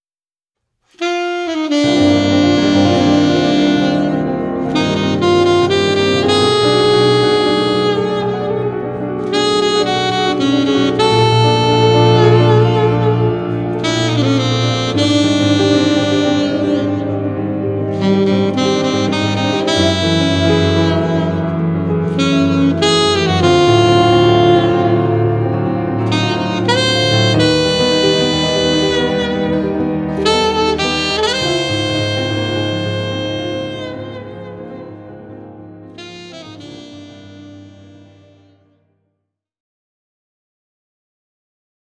スリリングながらも暖かいJAZZ愛に溢れるDuo作品！
Alto Sax
Piano